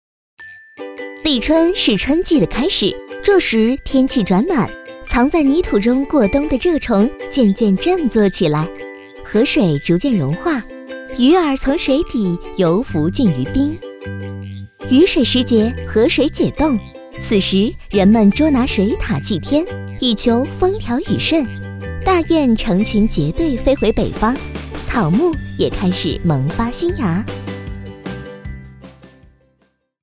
女国132_专题_产品_解说_清新.mp3